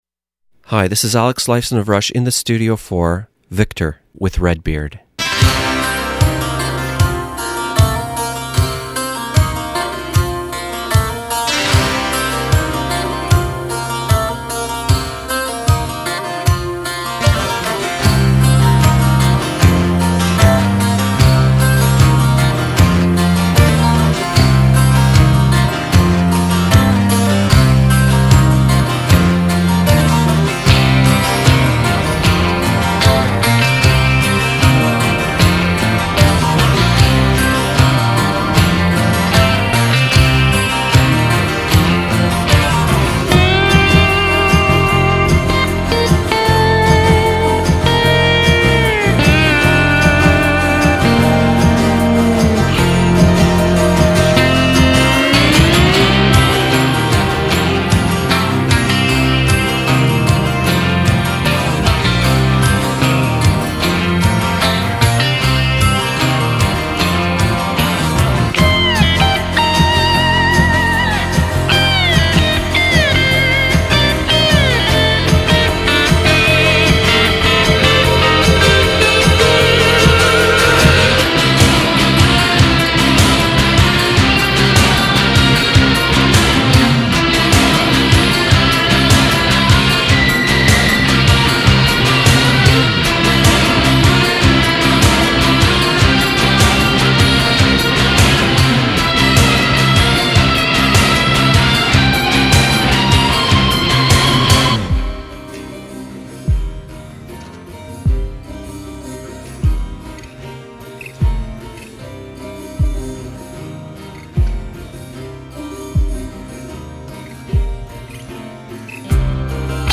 One of the world's largest classic rock interview archives, from ACDC to ZZ Top, by award-winning radio personality Redbeard.
Alex Lifeson lies down on the couch here In the Studio and reveals his confessions in my ultra-rare classic rock interview.